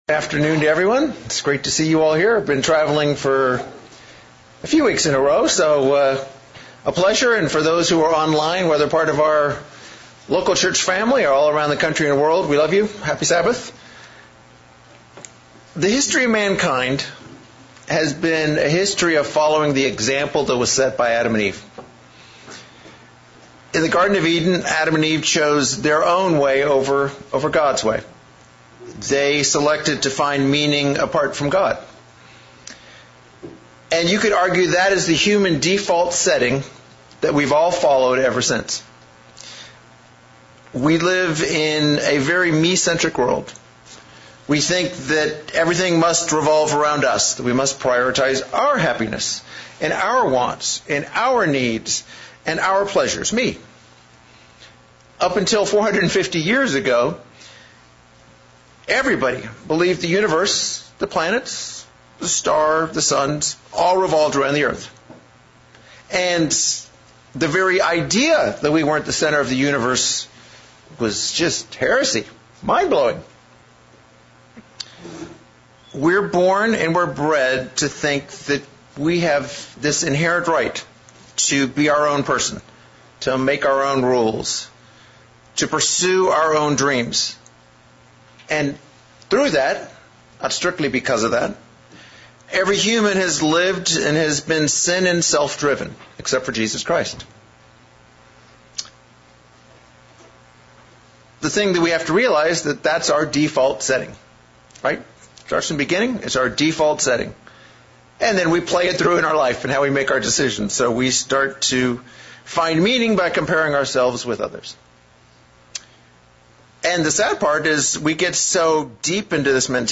The sermon addresses the excuses we have to overcome to move from our default setting and how to live to glorify God.